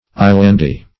Islandy \Is"land*y\, a. Of or pertaining to islands; full of islands.